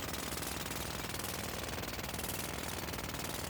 BackSound0684.wav